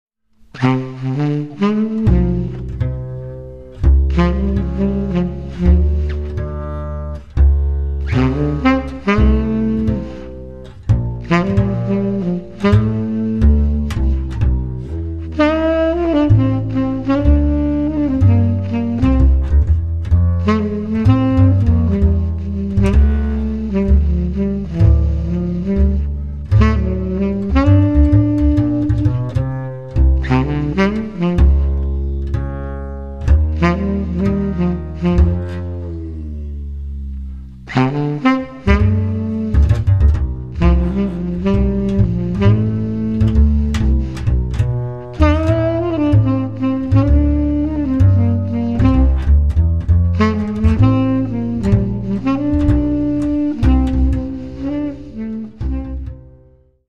tenor saxophone
bass